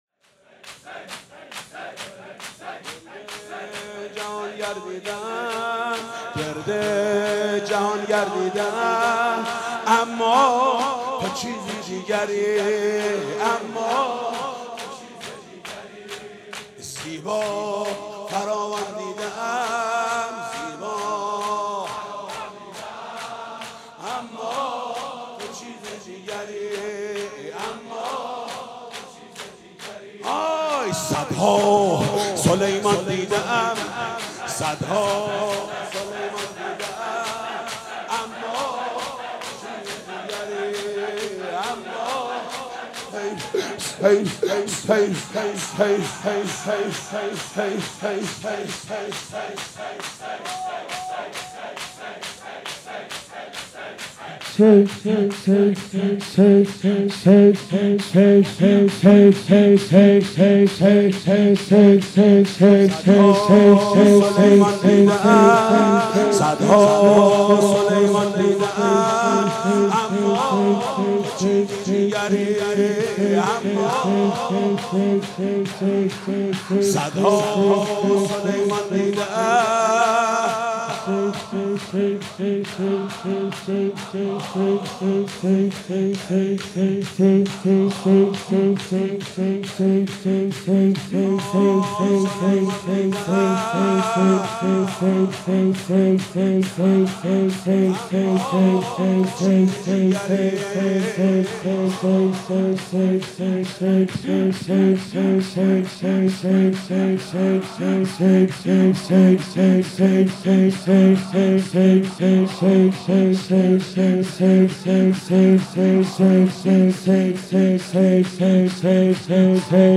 شور زیبا